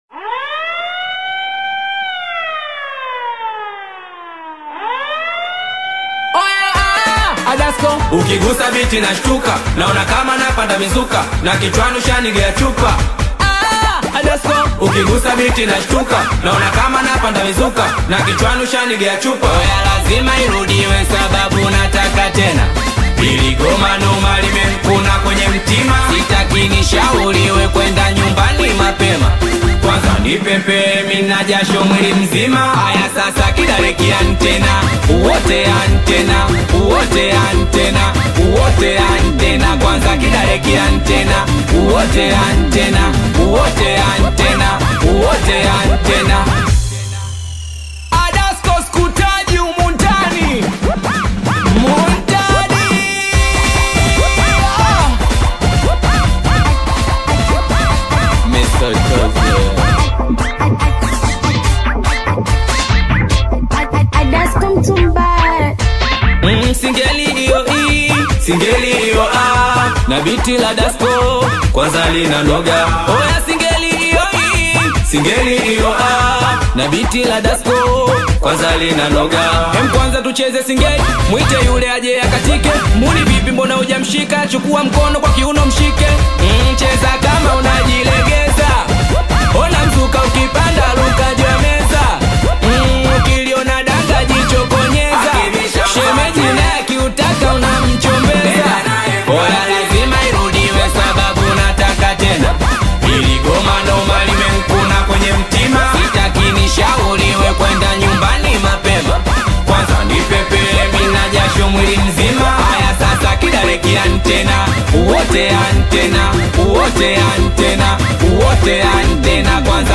Singeli music track
Tanzanian Bongo Flava artist, singer, and songwriter
Singeli song